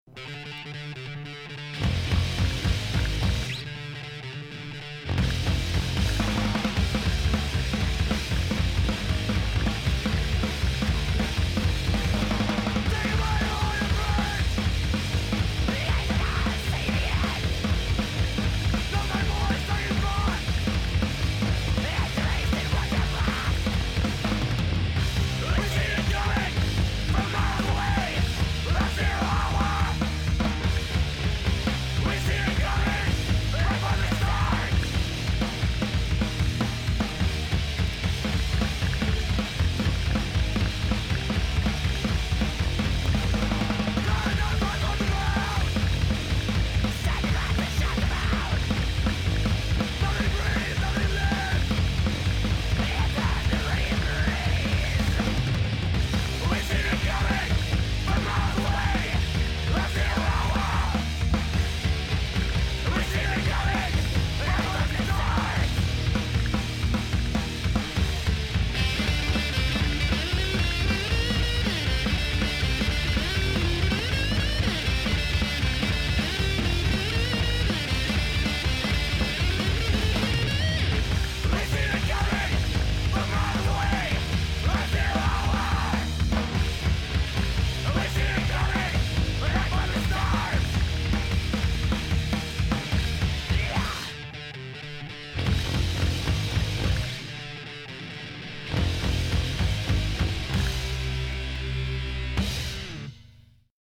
guitar, vocals, bass
guitar, vocals, drums